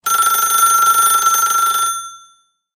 phone.ogg